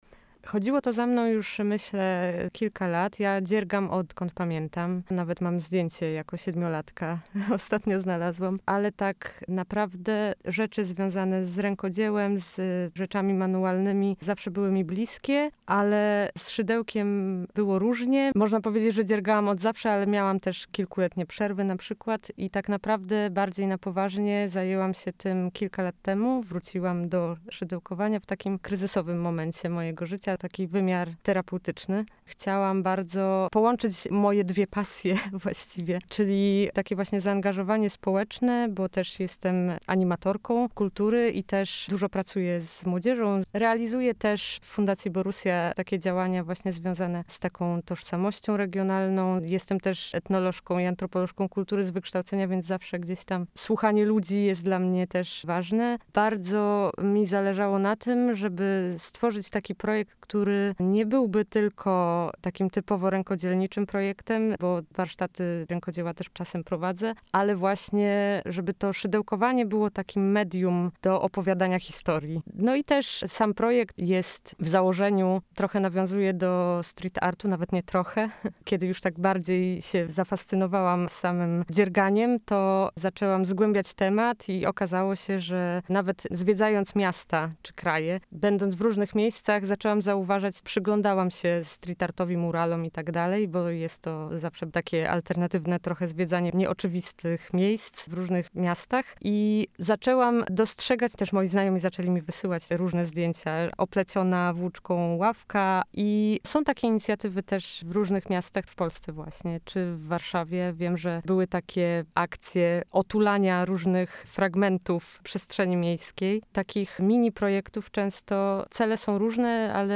O tym, na czym chcą się skupić, opowiedzieli także w studiu Radia UWM FM.